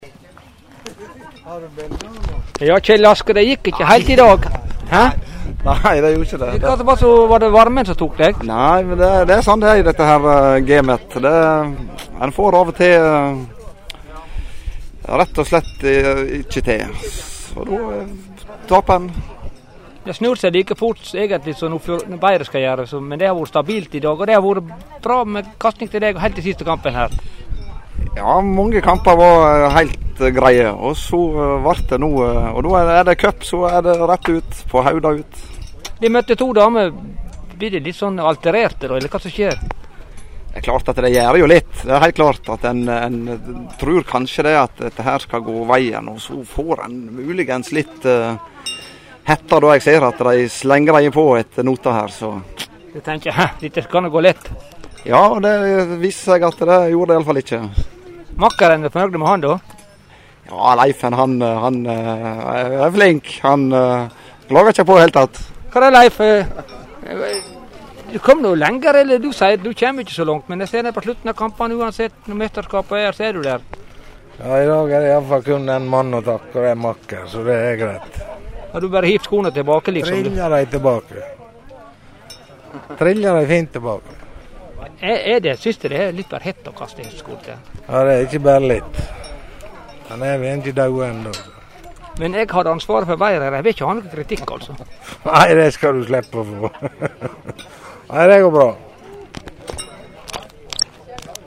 Lydklipp frå NM i Hesteskokasting
Intervju